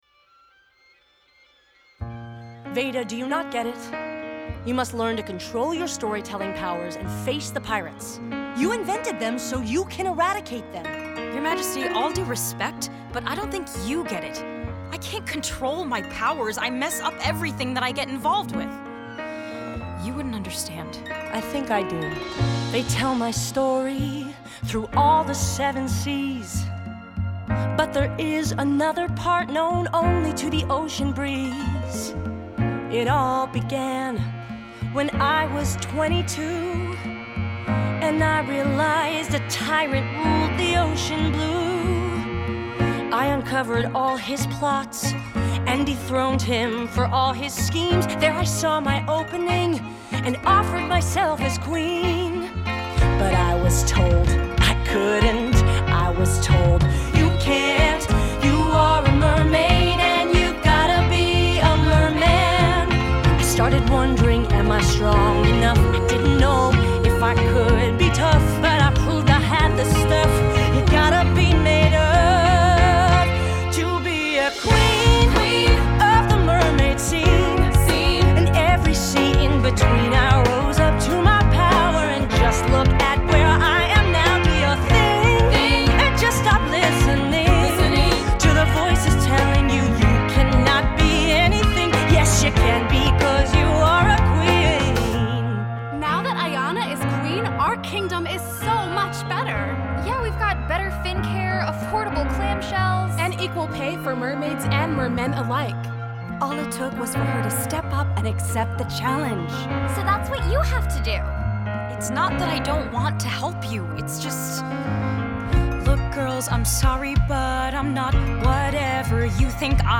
A mermaid chorus backs up Queen Iana as she urges Veida to, "Stop listening to the voices telling you, you cannot do anything."